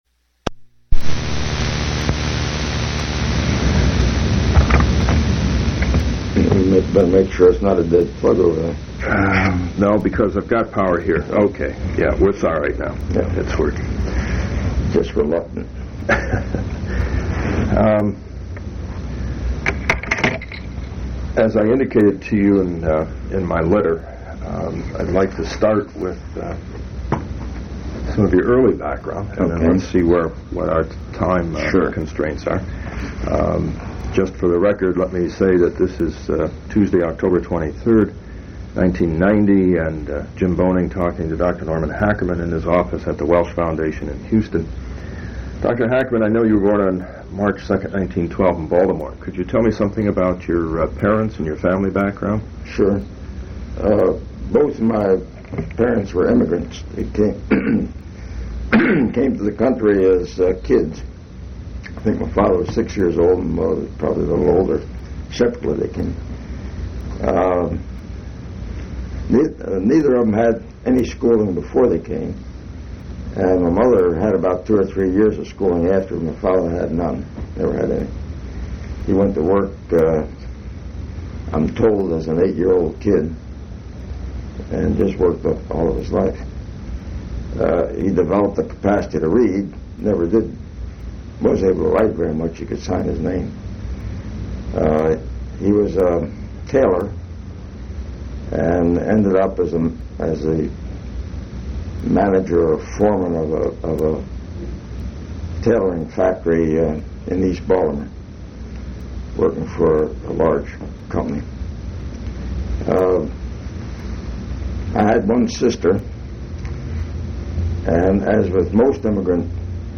Oral history interview with Norman Hackerman